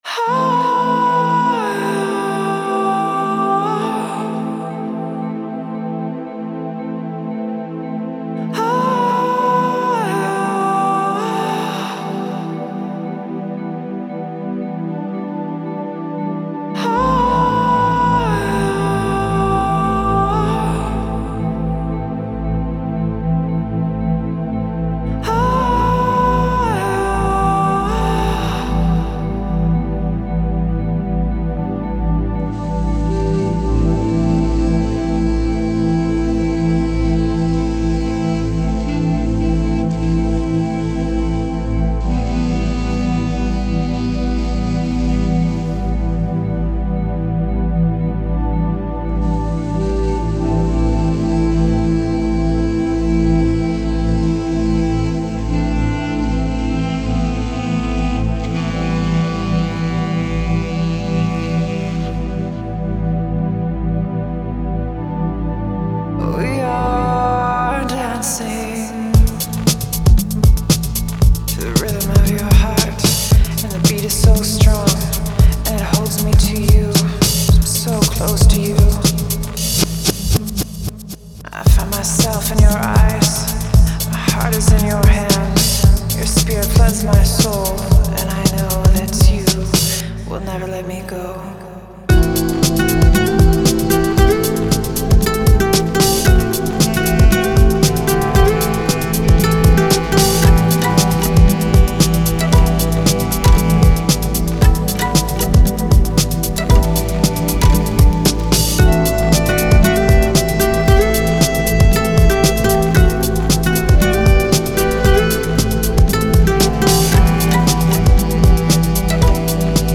Genre: Chillout, Lounge, Downtempo.